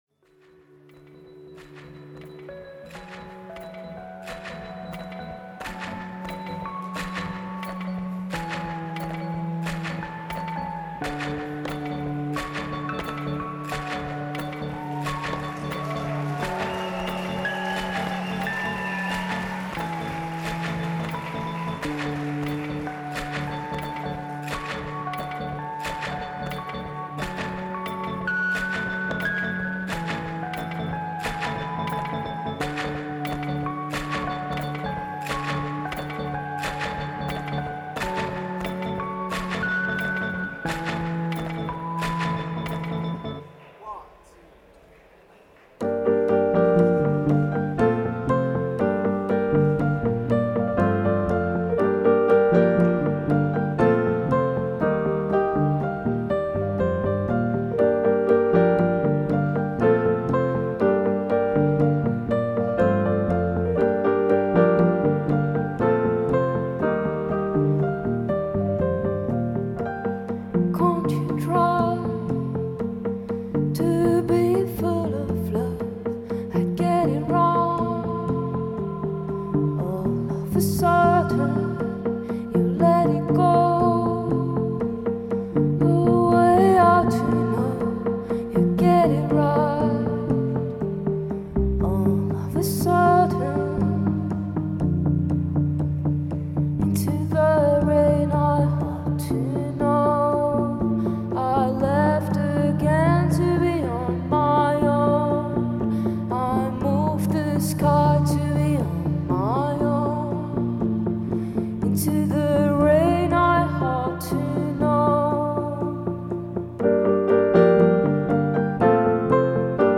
Post-Rock/Low-Fi ensemble
Low-fi with glimmers of melancholia from Belgium
Low-Fi, Melancholy and shrouded in mist